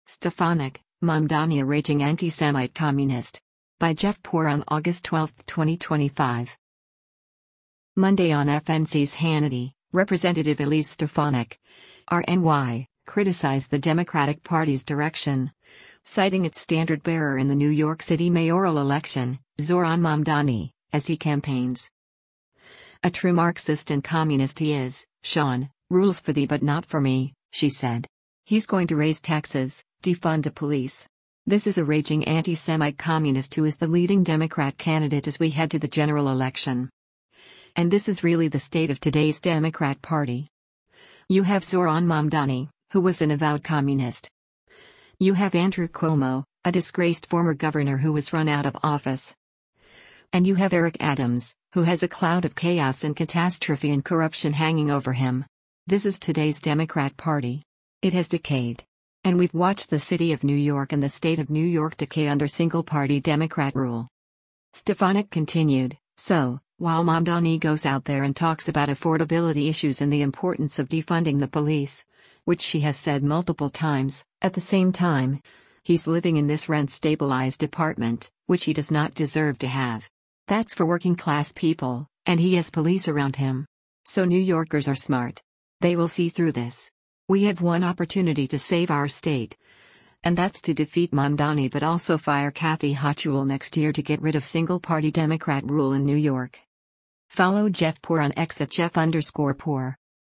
Monday on FNC’s “Hannity,” Rep. Elise Stefanik (R-NY) criticized the Democratic Party’s direction, citing its standard bearer in the New York City mayoral election, Zohran Mamdani, as he campaigns.